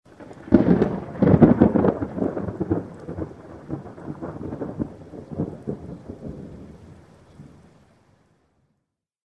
thunder_5.ogg